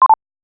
Beep Down